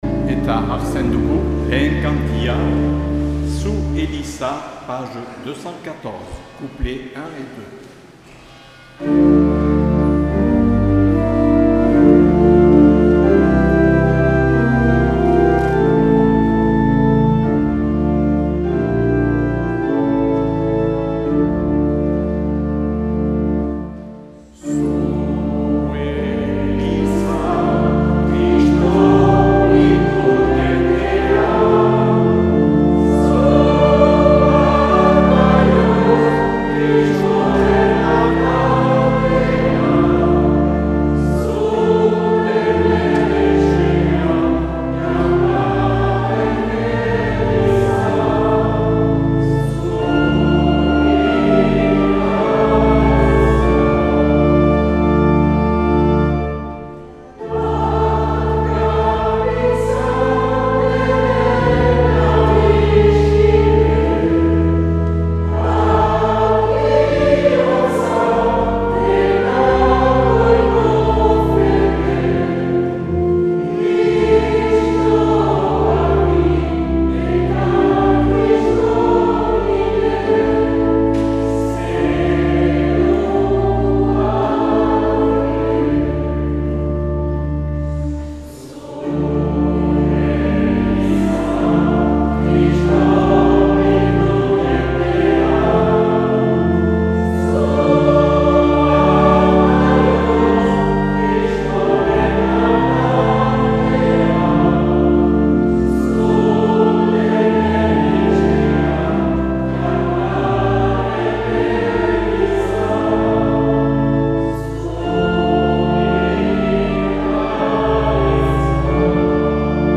Accueil \ Emissions \ Vie de l’Eglise \ Célébrer \ Igandetako Mezak Euskal irratietan \ 2021-06-13 Urteko 11.